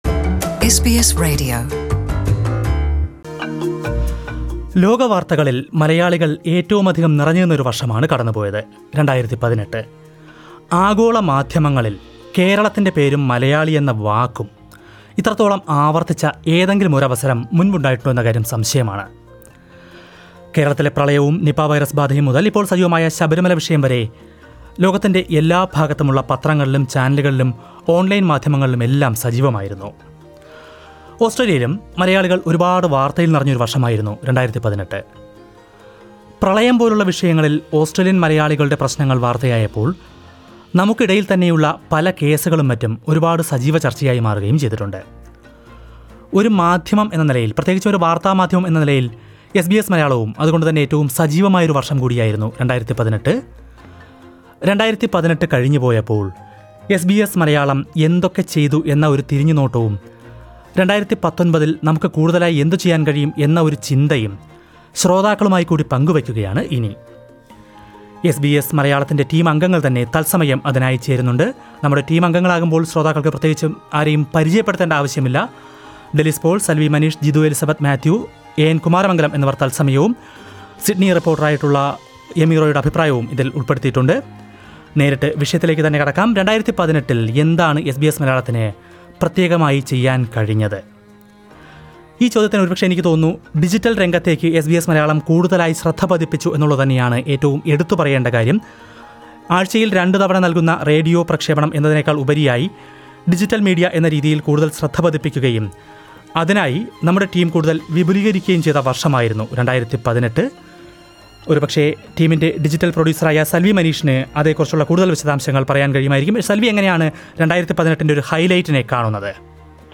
SBS Malayalam team gets together to think aloud.